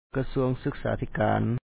Pronunciation Notes 25
kasúaŋ sýksǎathi-kaan Ministry of Education